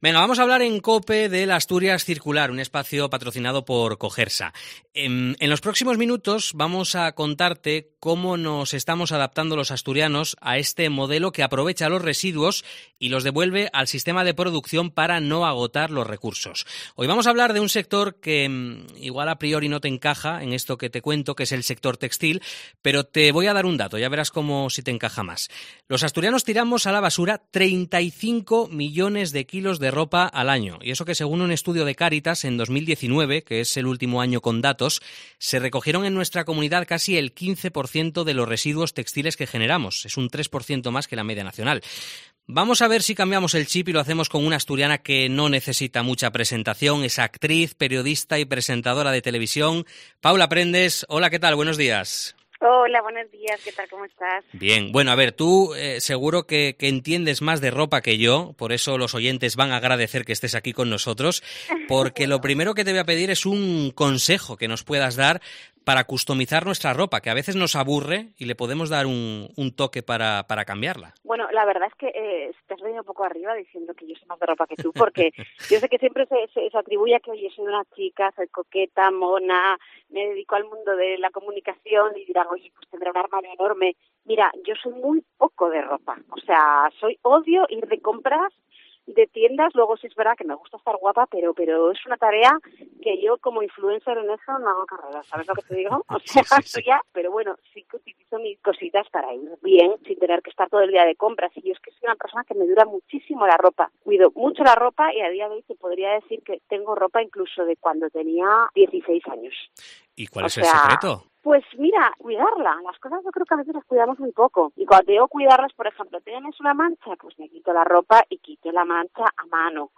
La economía circular en la moda: hablamos con la actriz y presentadora Paula Prendes
Lo haremos a través de la conversación con asturianos y asturianas que son líderes en sus sectores profesionales (hostelería, deporte, moda, arte, cultura, ciencia…) y que, además, enarbolan un compromiso claro con el medio ambiente y la sostenibilidad.